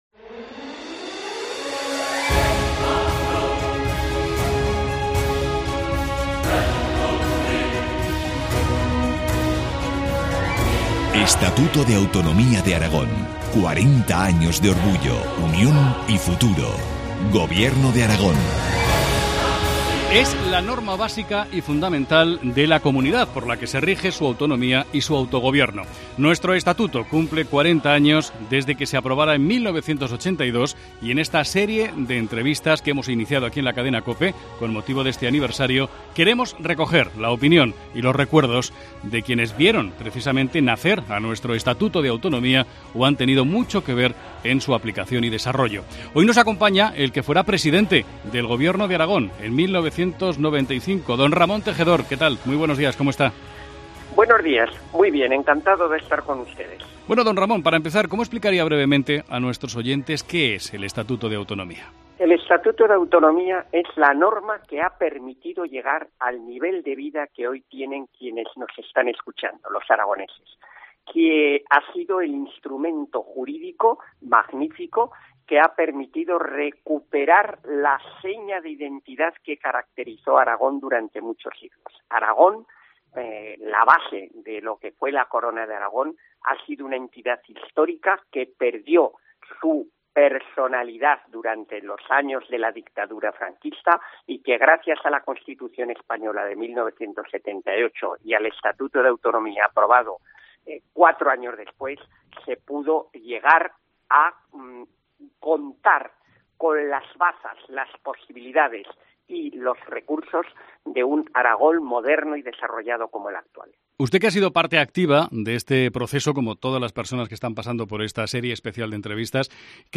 Entrevista a Ramón Tejedor, presidente del Gobierno de Aragón en 1995.